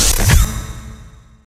[影视音效][高科技机械技能释放嗖][剪辑素材][免费音效下载]-8M资料网
本作品内容为高科技机械技能释放嗖， 格式为 mp3， 大小1 MB， 源文件无广告，欢迎使用8M资料网，为维护知识版权生态，如您认为平台内容存在版权争议，请通过官方反馈渠道提交书面权利通知，我们将在收到有效文件后依法及时处理。